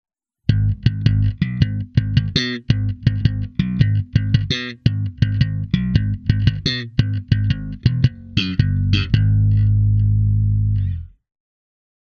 Here are a few sound clips that I’ve recorded with my trusty Jazz Bass, going via my Sans Amp Bass Driver DI into Pro Tools.
My own Jazz Bass is a Japanese Standard-model from 1985, strung with a Rotosoundin Swing Bass -set.
slapping/both PUs
jazz-bass_slap_bothpus.mp3